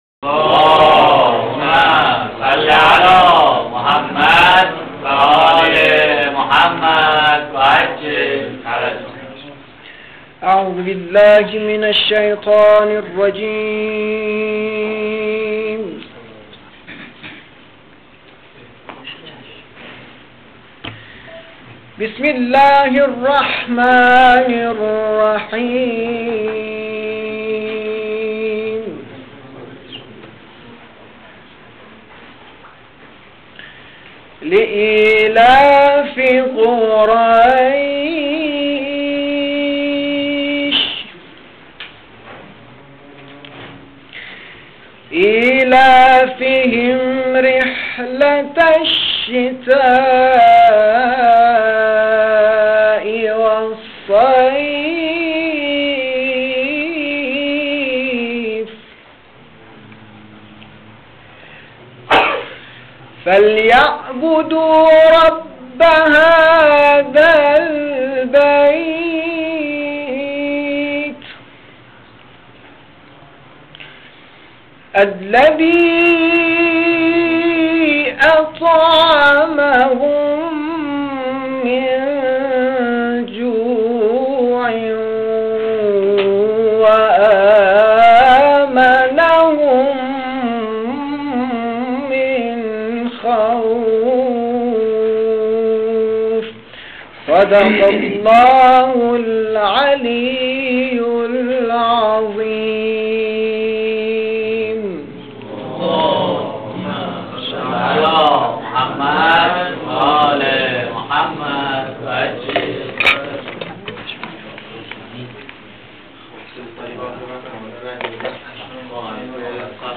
نشست علمی شانزدهم (پیش رویداد سوم)